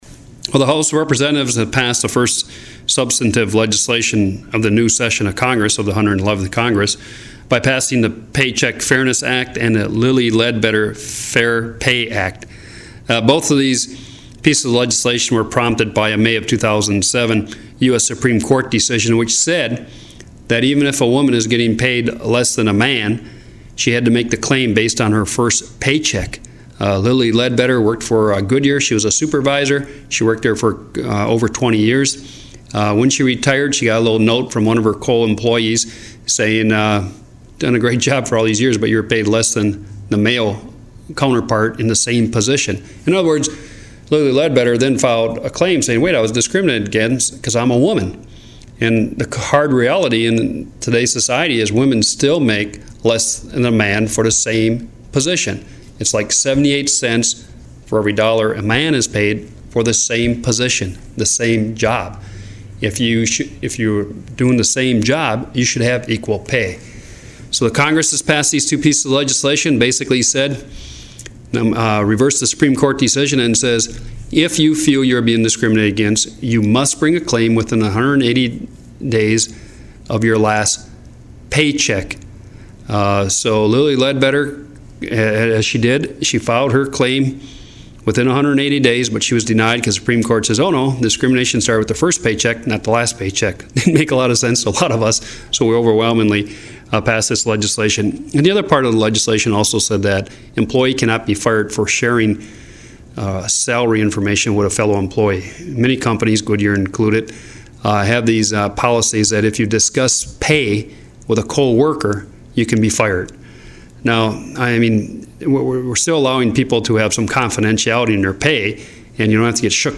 Congressman Bart Stupak – Comments on Equal Pay legislation heading through Congress.